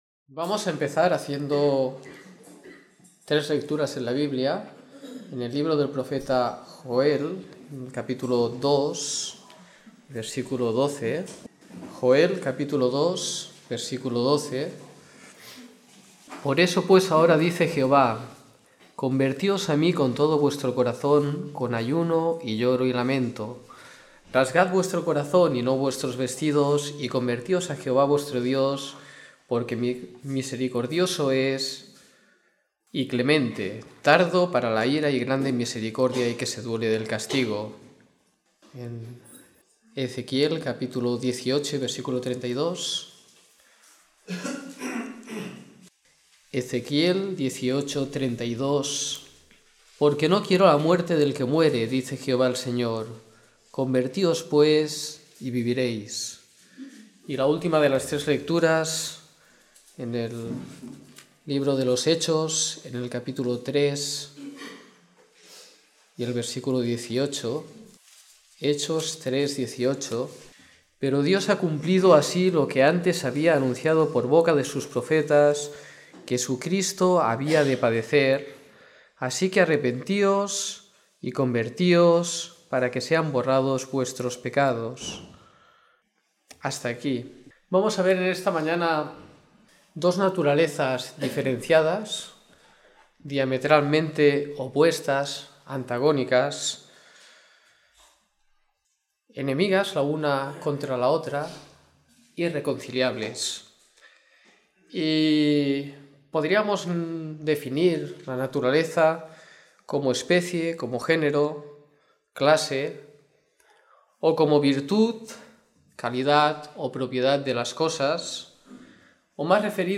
Escuchar la Reunión / Descargar Reunión en audio Hoy en día hay dos tipos de personas en la tierra con dos naturalezas antagónicas e irreconciliables.